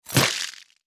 На этой странице собраны звуки копья: удары, скрежет, броски и другие эффекты.
Копьем вспарывают живот